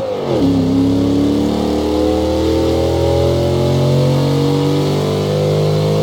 Index of /server/sound/vehicles/lwcars/chev_suburban